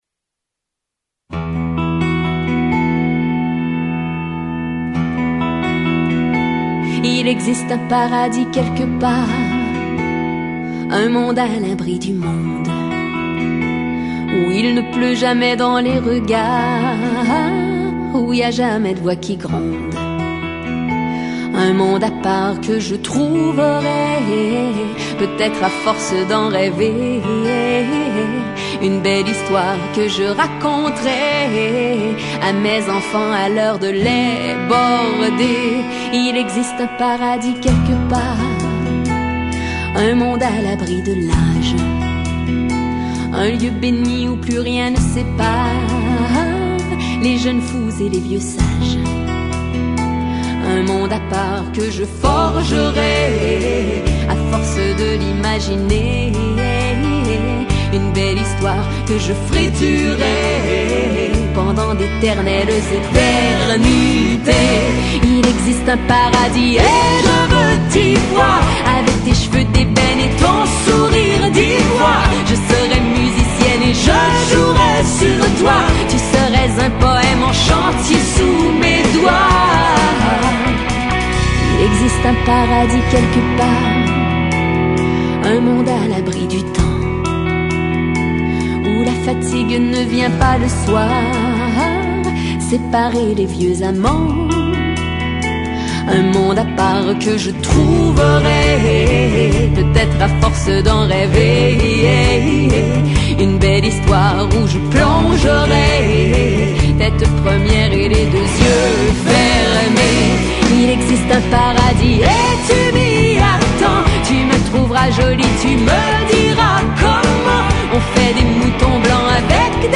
Novodobá kanadská šansoniérka.